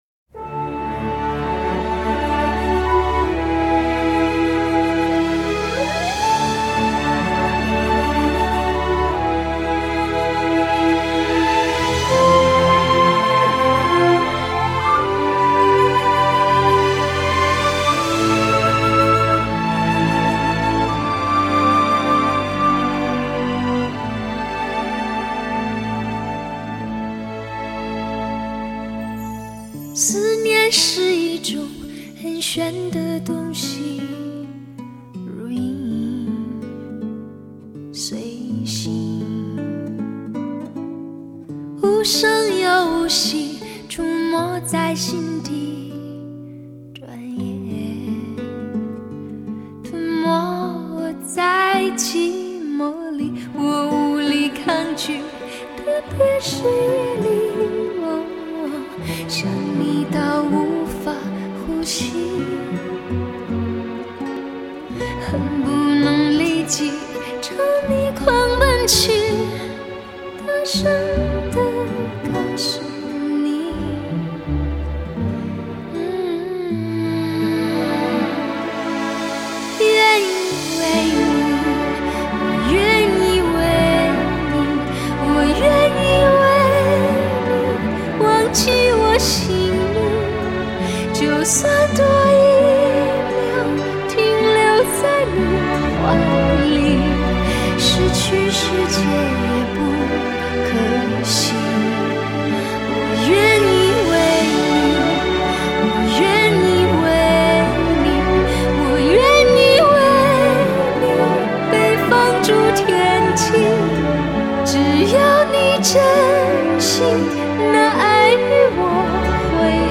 立体环绕音效 德国母带后期录制
当每个音符响起，那些熟悉充满质感的声音，填满了音乐与你之间的距离，不变的旋律，不变的感动。